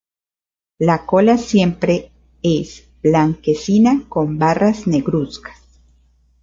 co‧la
Uitgespreek as (IPA)
/ˈkola/